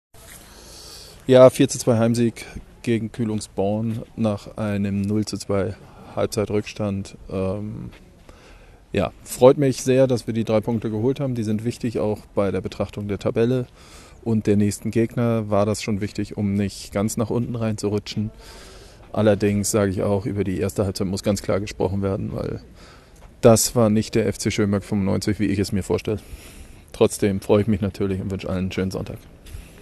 Stimmen zum Spiel